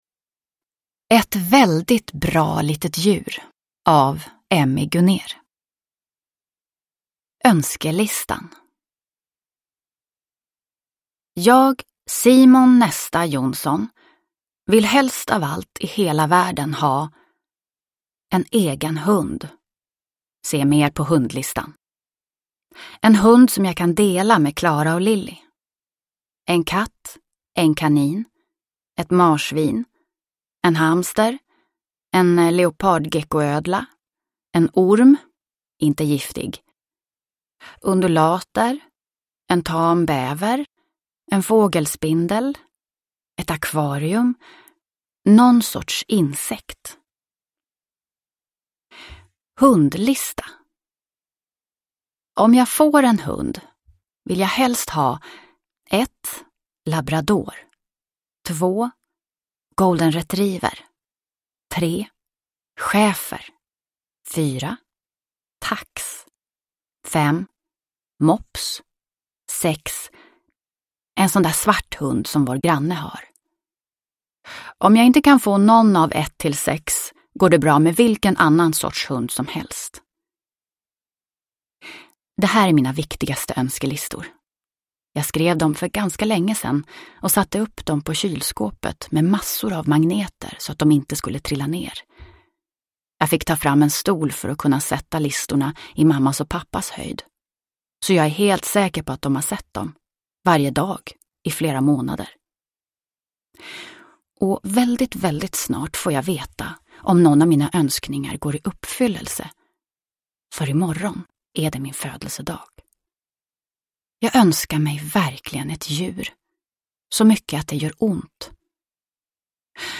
Ett väldigt bra litet djur – Ljudbok – Laddas ner